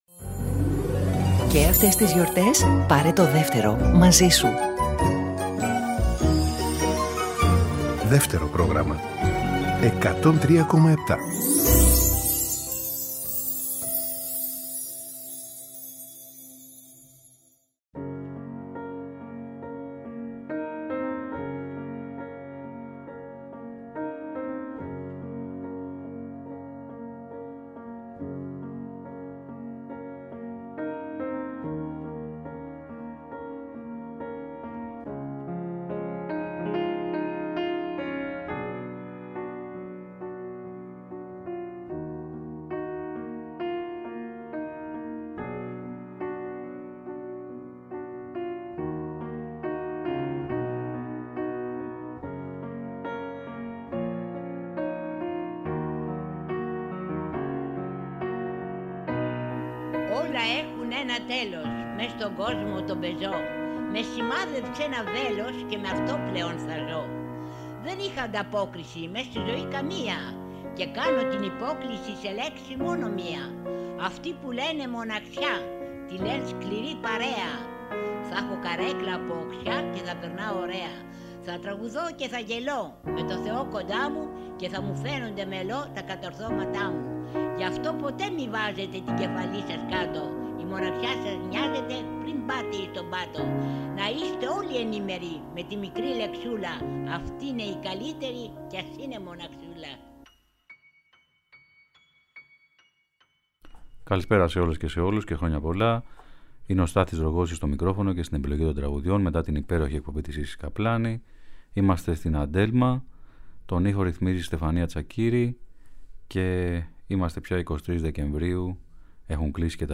ελληνικά τραγούδια
με τραγούδια γιορτινά, γλυκά και χριστουγεννιάτικα